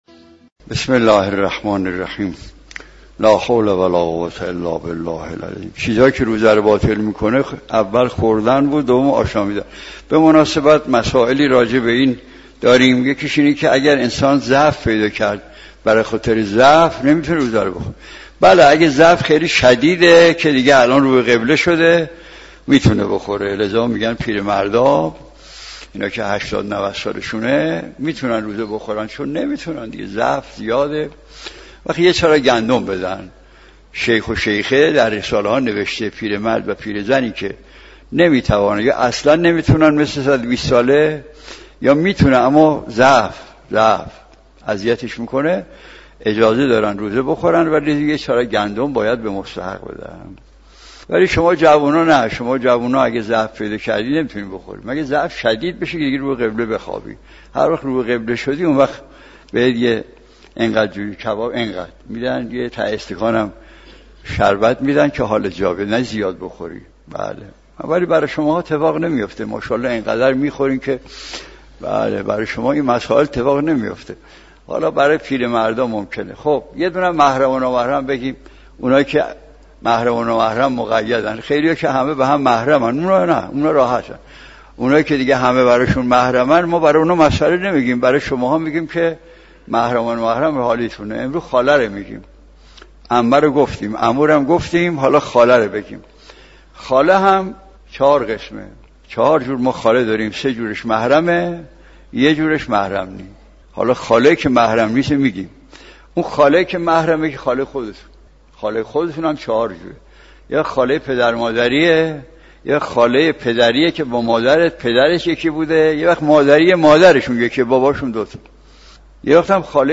سخنرانی آیت‌الله مجتهدی تهرانی را در ادامه می‌شنوید.